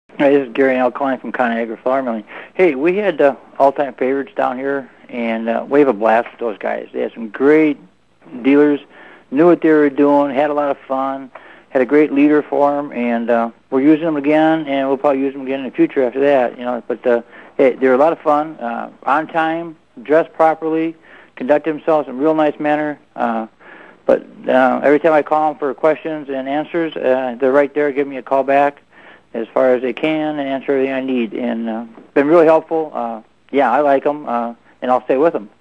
Listen to one of our customers audio comments about one of our casino vendors